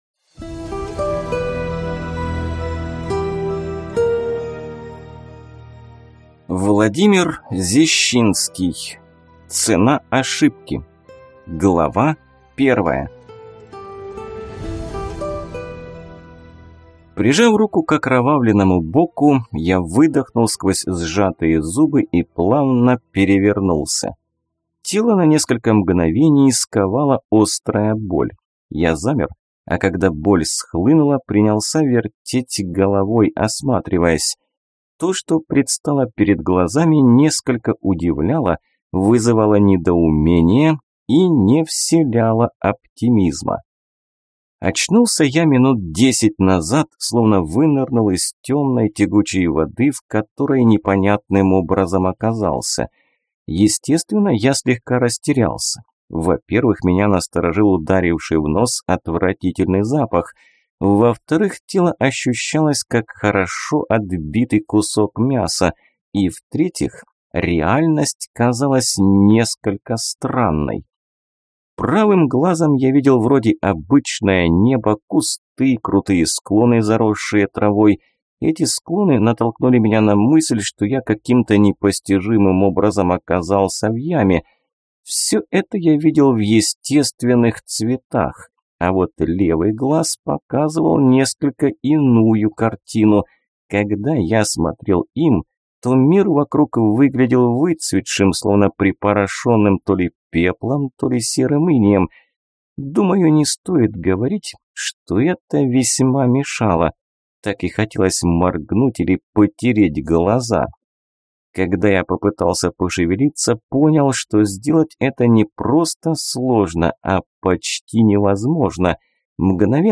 Аудиокнига Цена ошибки | Библиотека аудиокниг